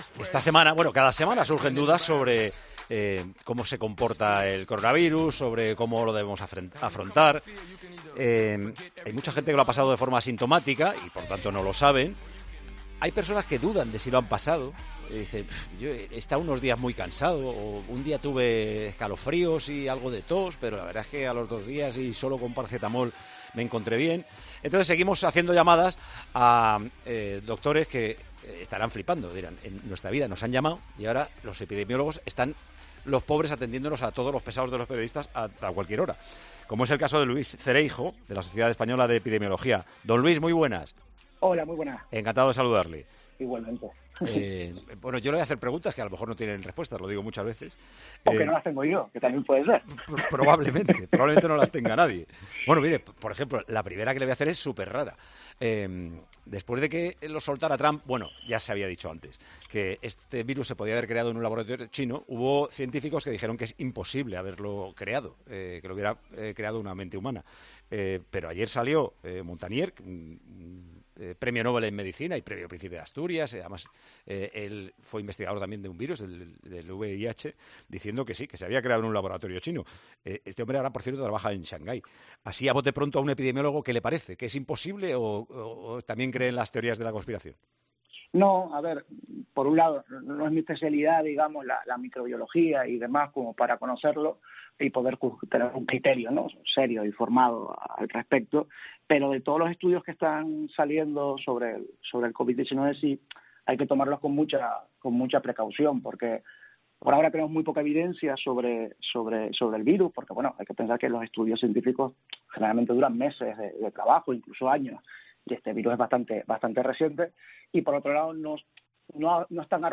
charlamos con el epidemiólogo